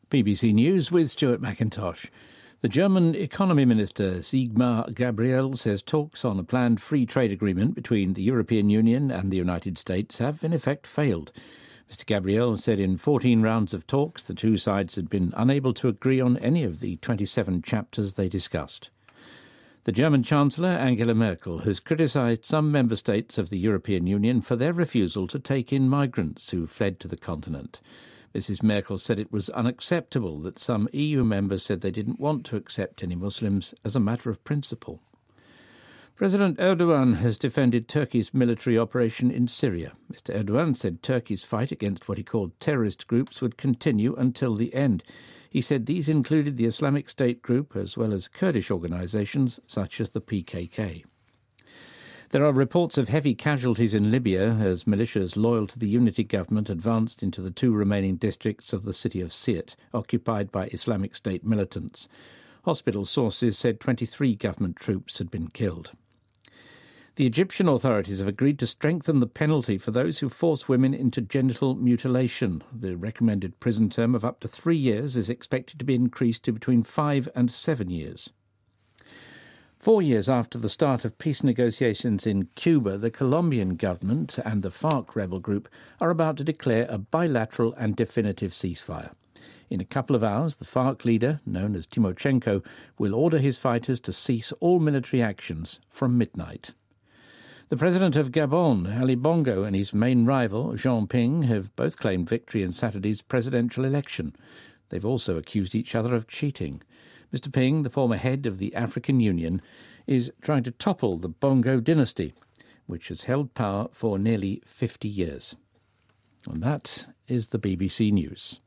BBC news,欧盟与美国自由贸易谈判宣告失败
日期:2016-08-31来源:BBC新闻听力 编辑:给力英语BBC频道